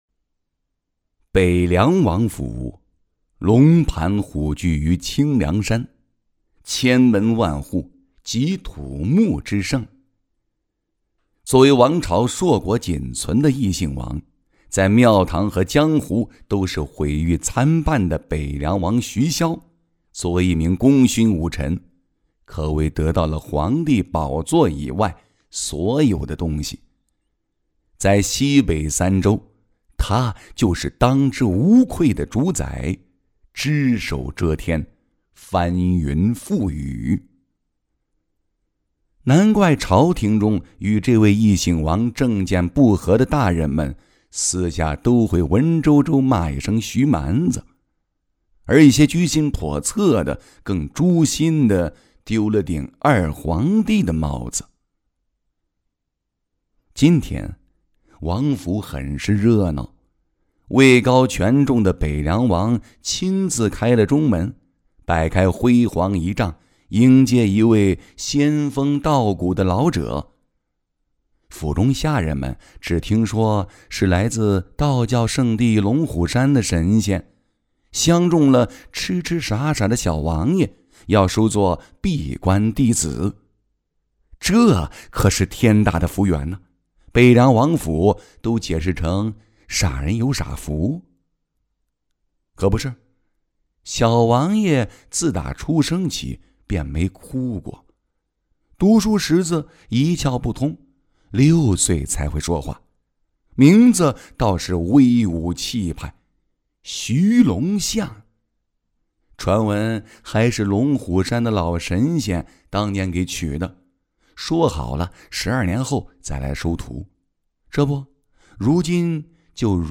国语中年素人 、男动漫动画游戏影视 、看稿报价男5 国语 男声 有声书 玄幻修真小说-紫阳 素人